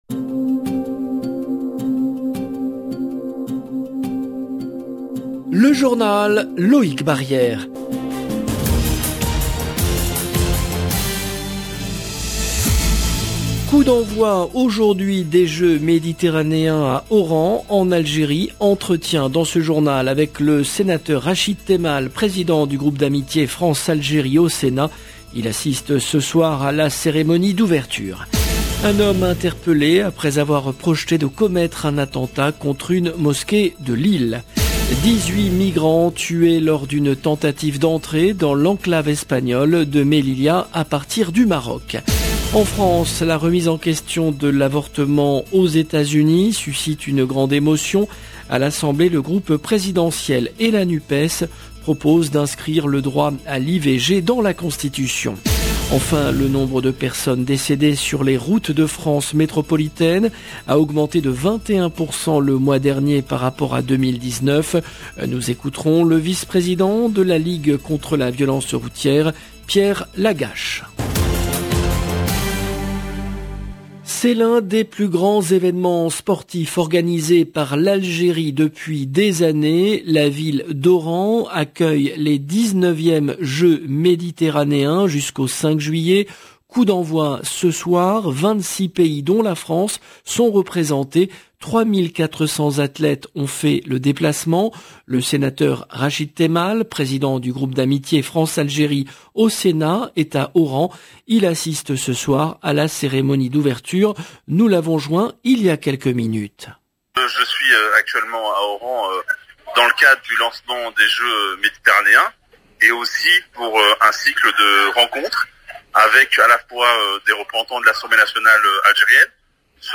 Coup d’envoi aujourd’hui des Jeux Méditerranéens à Oran, en Algérie. Entretien avec le sénateur Rachid Temal, président du groupe d’amitié France/Algérie au Sénat.